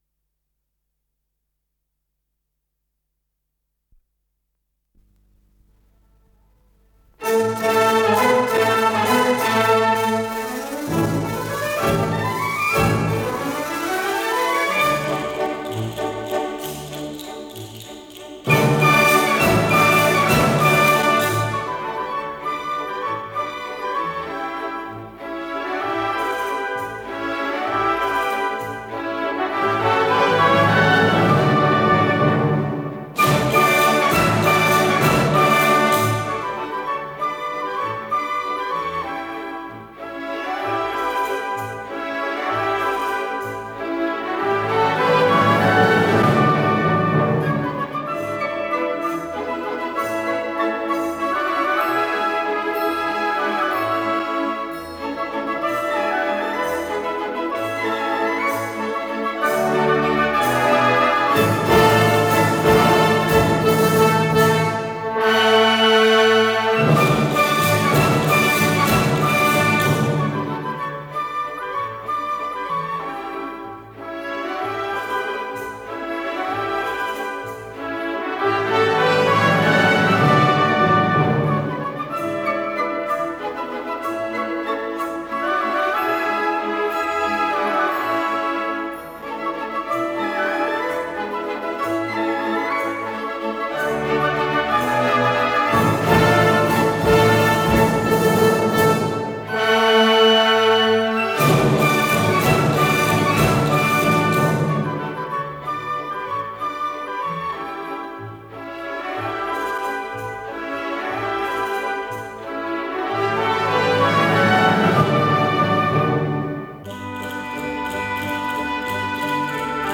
с профессиональной магнитной ленты
ИсполнителиСимфонический оркестр Латвийского Т и Р
ВариантДубль моно